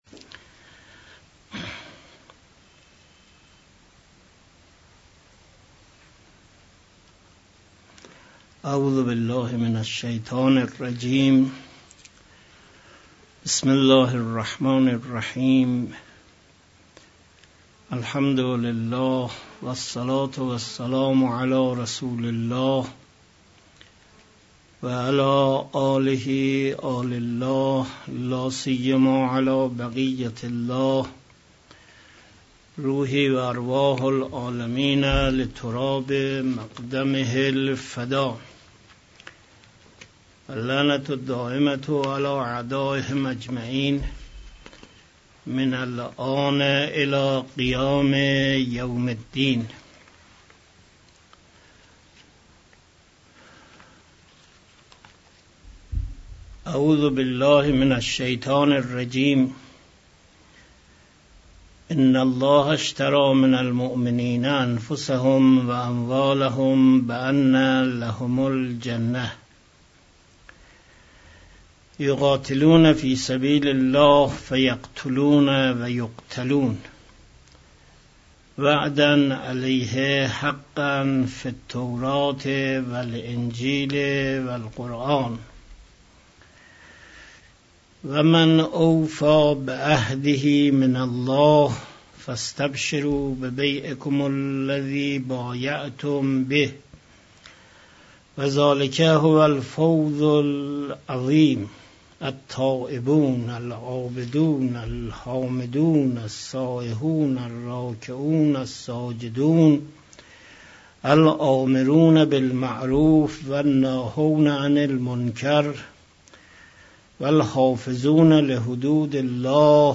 درس دوم توضیح آیات